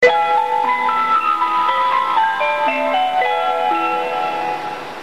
路線毎に異なる曲が使用されており、起終点で流れます。